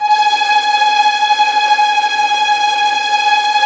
Index of /90_sSampleCDs/Roland L-CD702/VOL-1/STR_Vlns Tremelo/STR_Vls Trem wh%